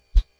whoosh1.wav